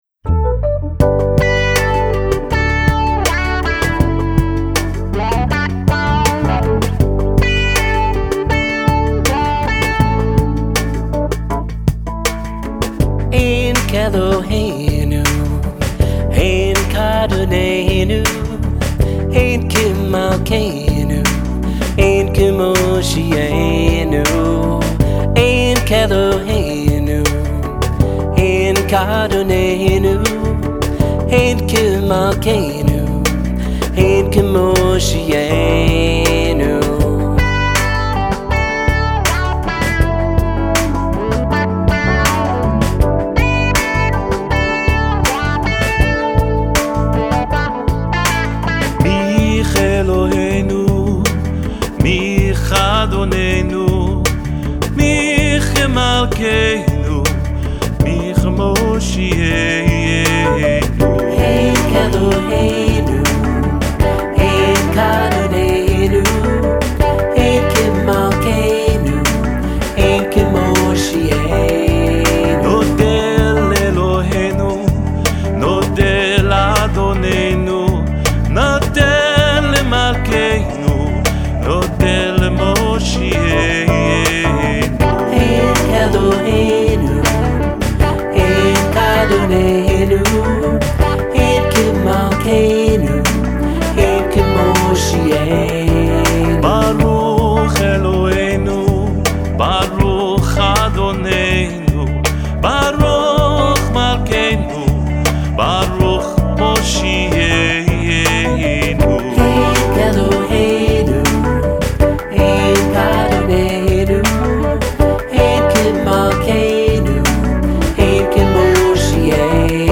contemporary Shabbat music